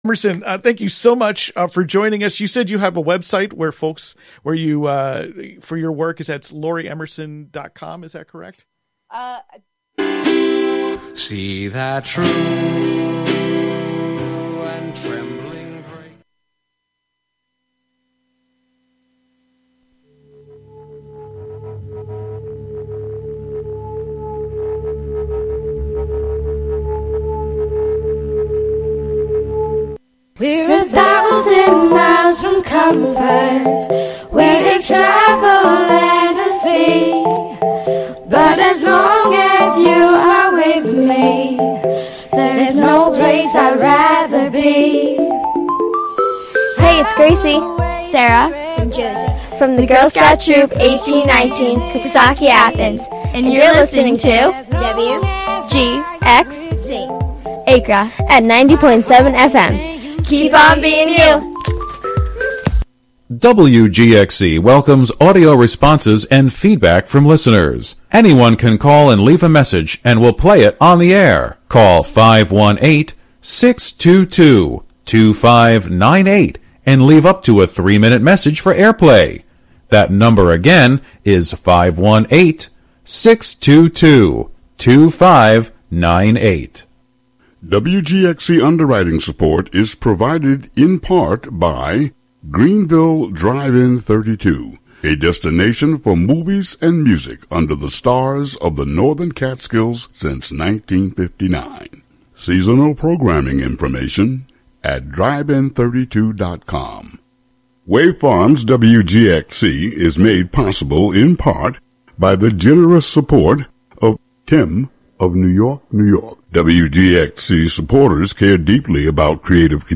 Also included are reflections on the recent presidential election, touching on the historic candidacy of Vice President Kamala Harris and her historic place in America as a Black woman of African (by way of Jamaica) and South Asian (India) descent. Though the outcome may not have been what many had hoped for, the conversation will center on how to find gratitude in moments of challenge and how resilience can guide us forward. Listeners are encouraged to call in during the Roundtable portion and share, along with our Special guests, what they’re most thankful for and any reflections of how our past may guide us to future progress.